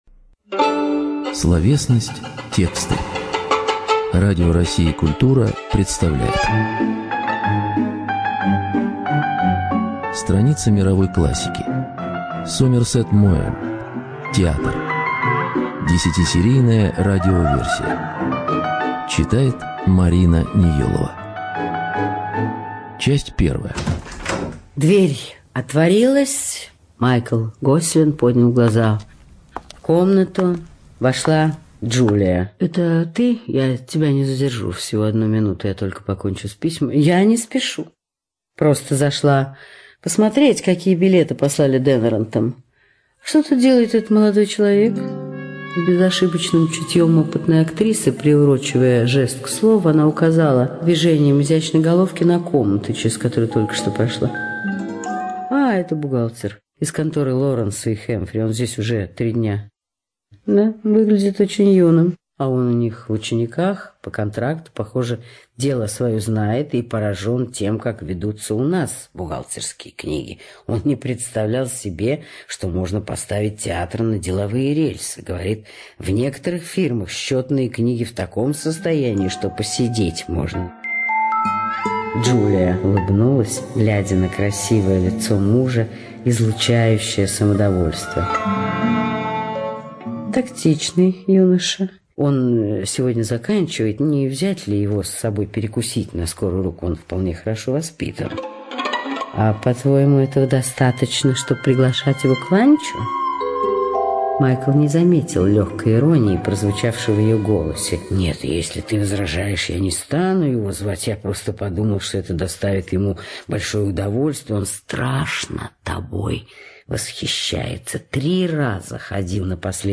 ЧитаетНеелова М.